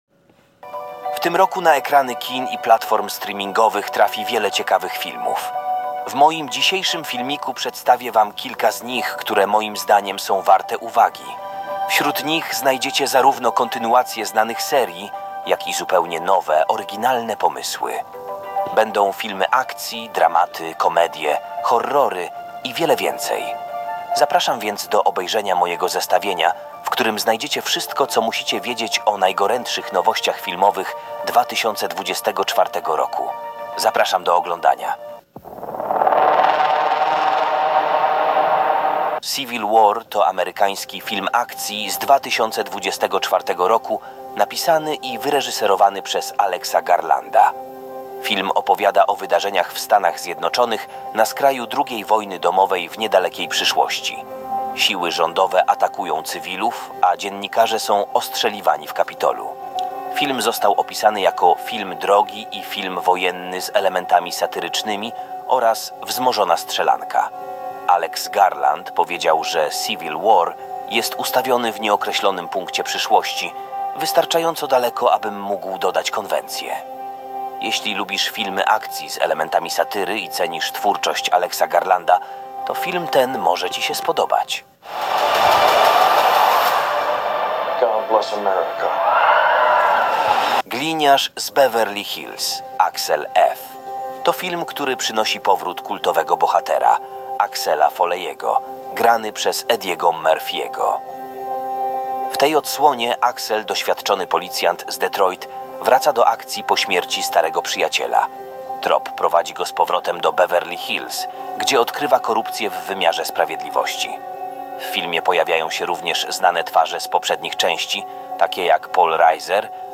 Nie ten filmik a jedynie jego ścieżkę dźwiękową, krótki kawałek, maks do 4 min ( powiedzmy ) dyktafonem ( apką w smartfonie )
Po zmianie ustawień dźwięku, dalej jest dobrze